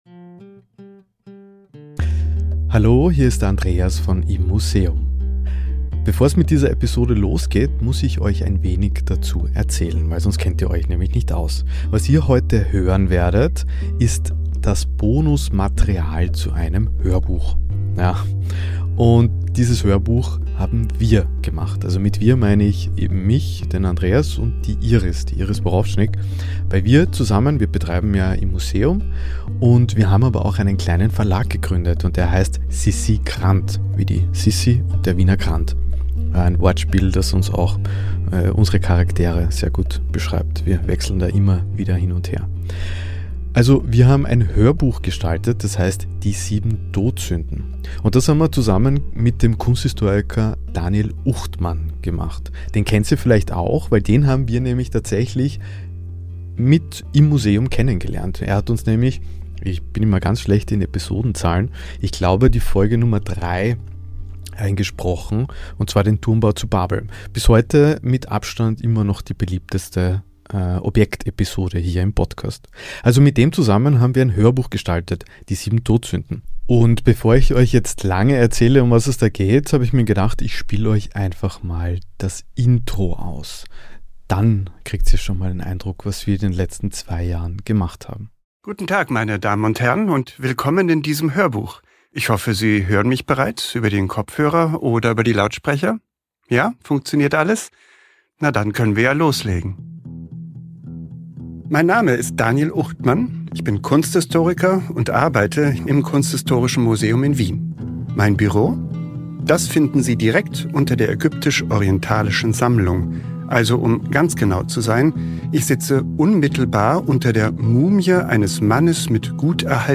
Heute gibt es ein Gespräch